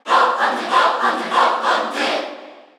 Category: Crowd cheers (SSBU) You cannot overwrite this file.
Duck_Hunt_Cheer_Korean_SSBU.ogg